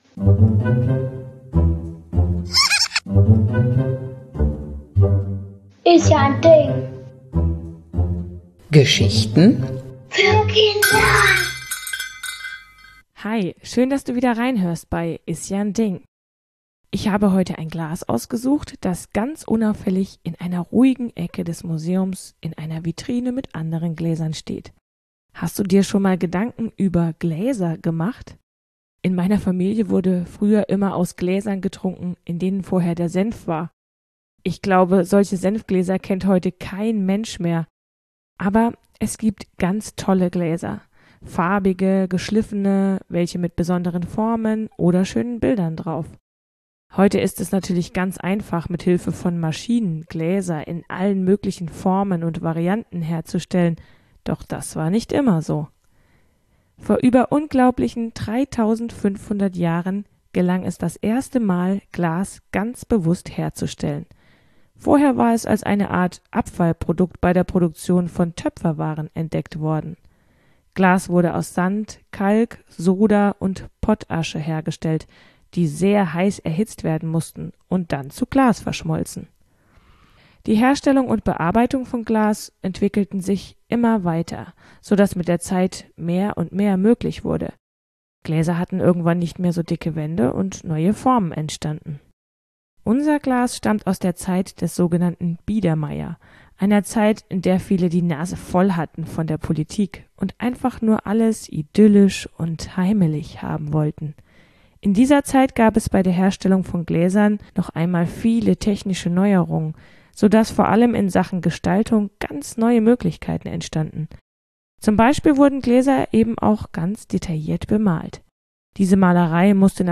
Geschichten für Kinder